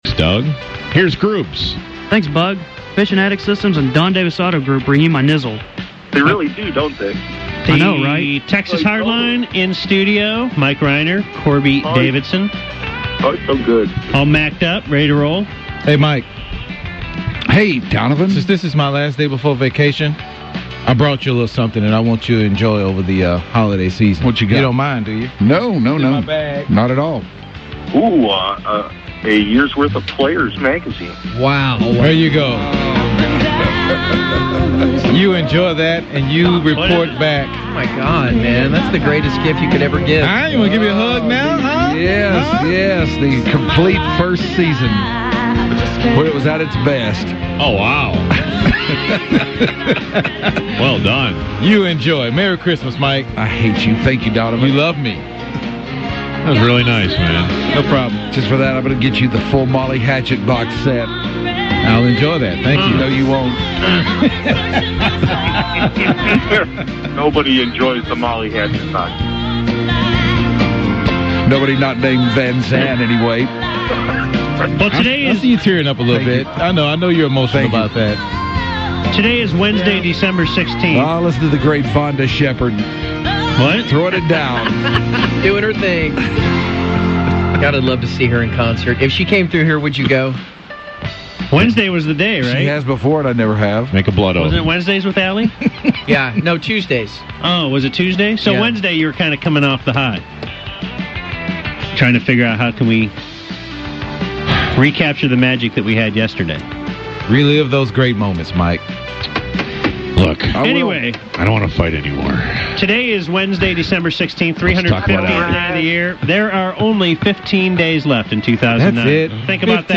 Reply That’s greatness, but the singing in the background is a complete ass whip.